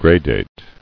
[gra·date]